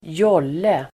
Ladda ner uttalet
jolle substantiv, dinghy Uttal: [²j'ål:e el. ²j'ul:e] Variantform: även julle Böjningar: jollen, jollar Synonymer: båt Definition: liten rodd- el. segelbåt dinghy substantiv, jolle yawl substantiv, jolle , liten skeppsbåt
jolle.mp3